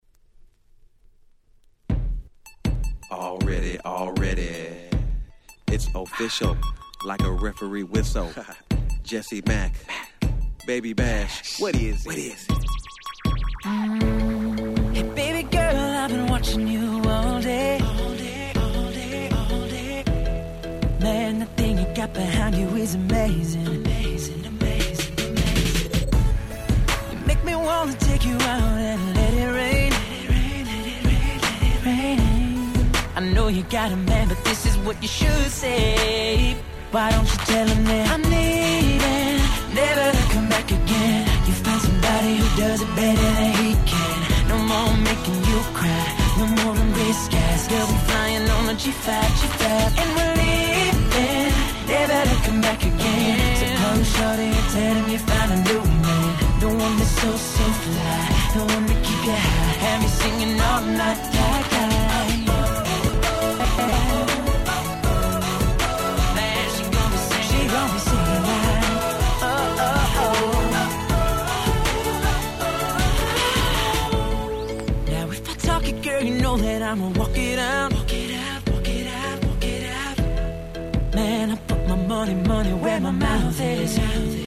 08' Nice R&B !!